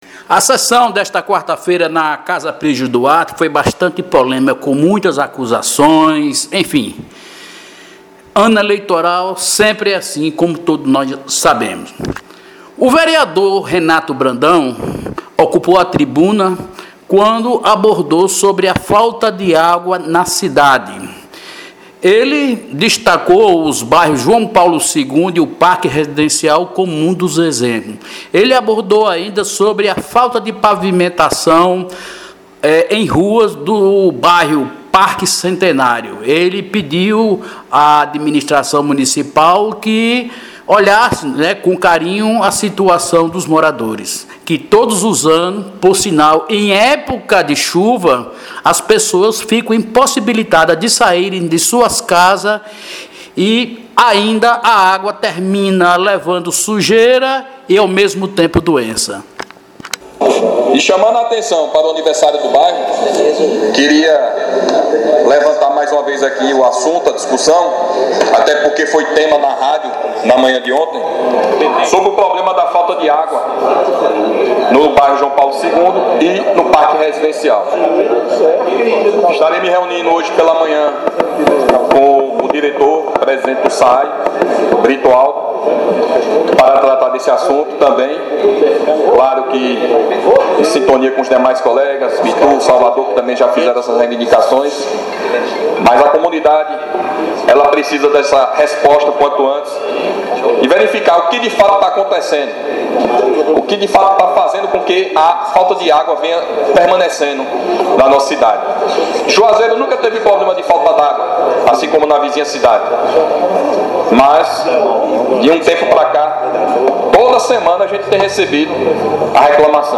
A sessão ordinária na Câmara de Vereadores de Juazeiro, nesta quarta-feira (18), foi recheada de polêmicas entre oposição e situação.